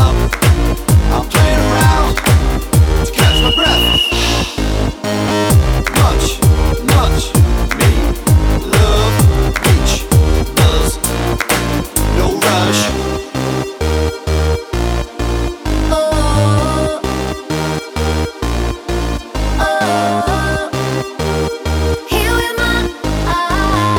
no Backing Vocals Dance 3:32 Buy £1.50